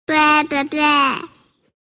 Bleh Bleh Bleh